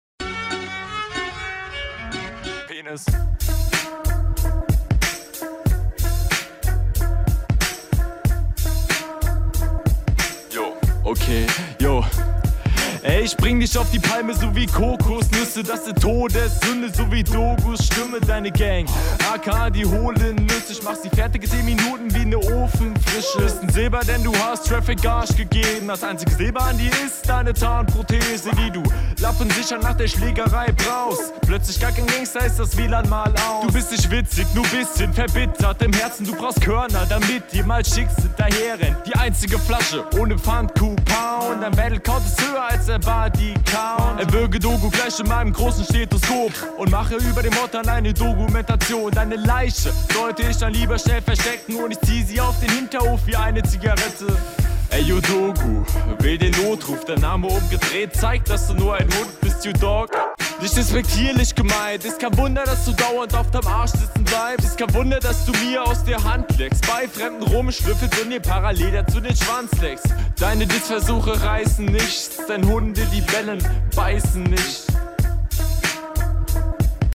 Finde du hast hier einen guten Flow, aber safe noch bisschen ausbaufähig.
Stabil gerappt.